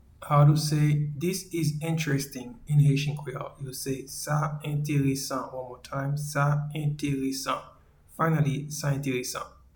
Pronunciation:
This-is-interesting-in-Haitian-Creole-Sa-enteresan.mp3